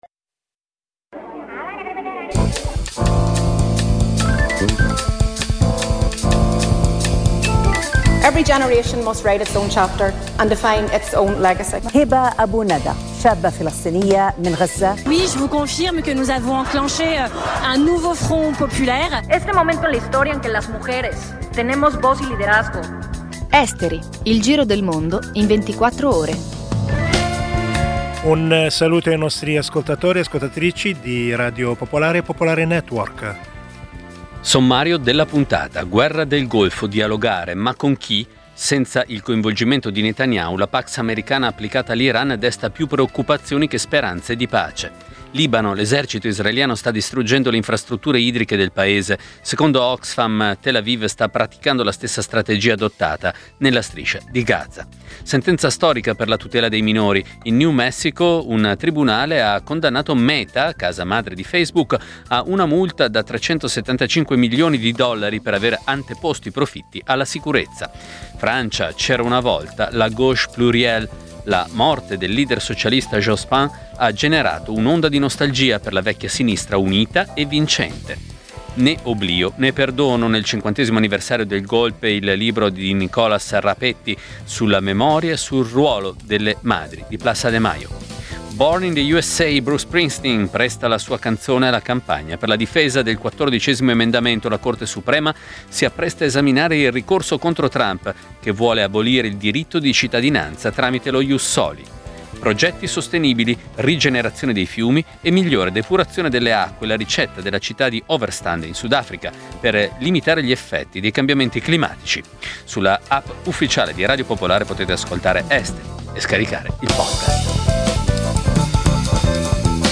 Il programma combina notizie e stacchi musicali, offrendo una panoramica variegata e coinvolgente degli eventi globali.